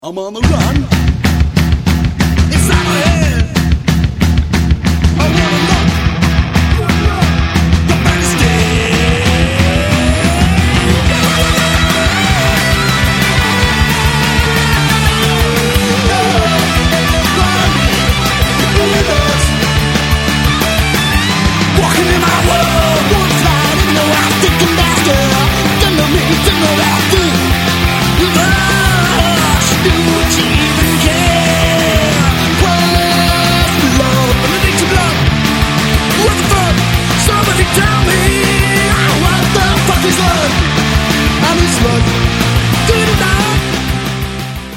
And Local punk-edged rockers